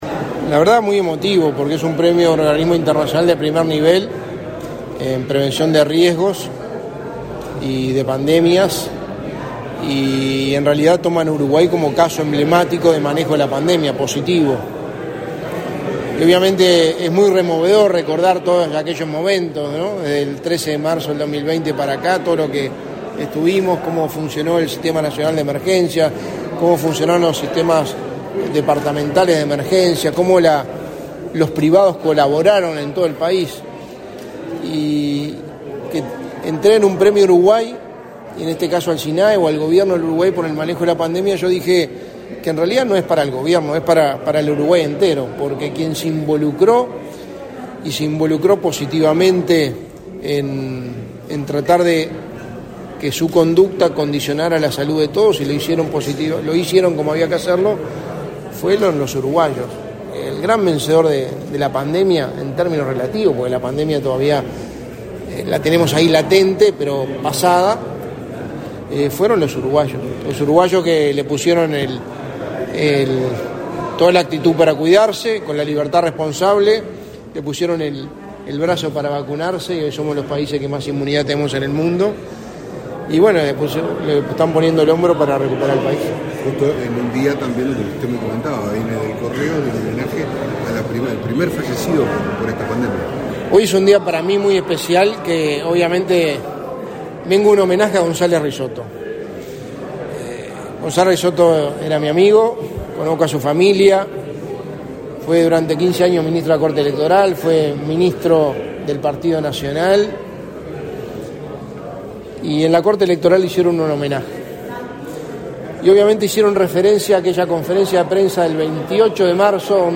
Declaraciones a la prensa del secretario de la Presidencia, Álvaro Delgado
El Centro Roger Kaufman y la Sociedad Internacional para la Mejora del Desempeño entregaron, este 28 de marzo, el reconocimiento al Gobierno de Uruguay a través del Sistema Nacional de Emergencias (Sinae), por su gestión durante la pandemia por COVID-19. Tras el evento, el secretario de la Presidencia de la República, Álvaro Delgado, realizó declaraciones a la prensa.